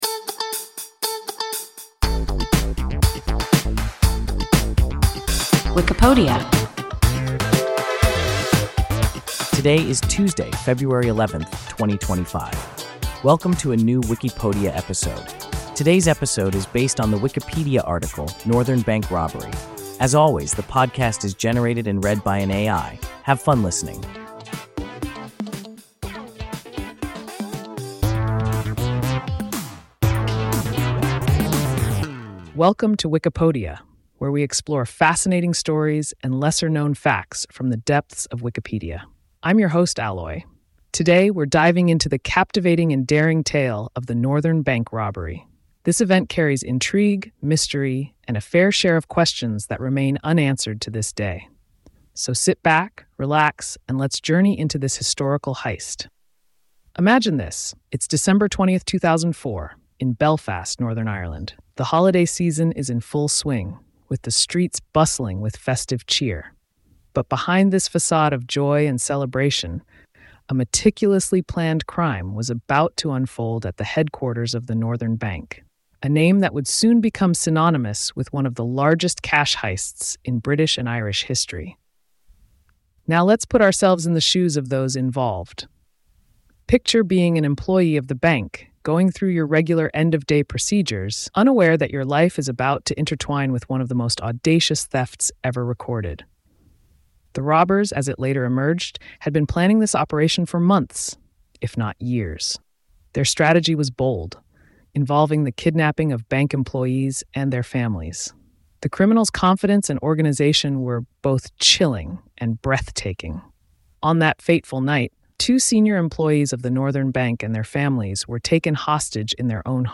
Northern Bank robbery – WIKIPODIA – ein KI Podcast